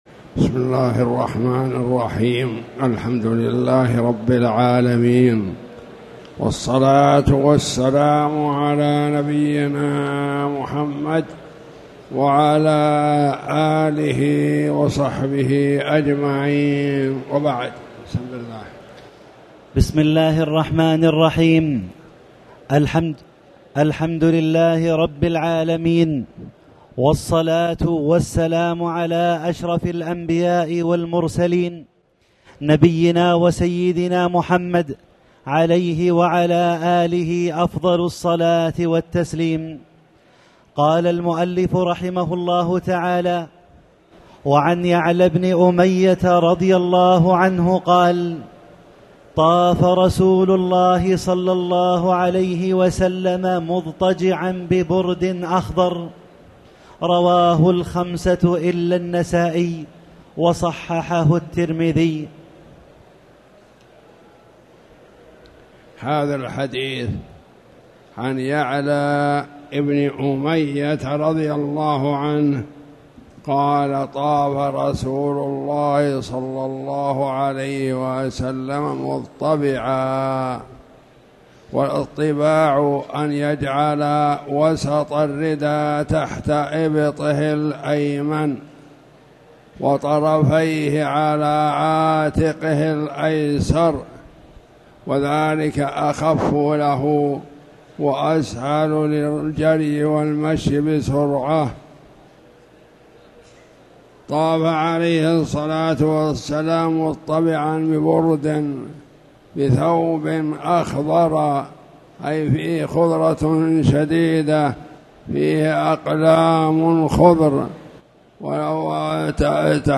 تاريخ النشر ٢٦ ذو الحجة ١٤٣٨ هـ المكان: المسجد الحرام الشيخ